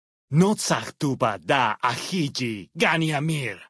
Dead Horses pidgin audio samples Du kannst diese Datei nicht überschreiben.